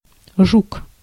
Ääntäminen
IPA: /ʐuk/